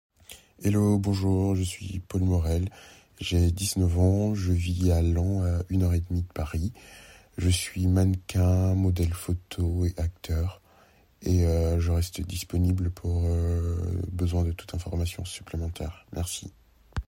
voix
- Ténor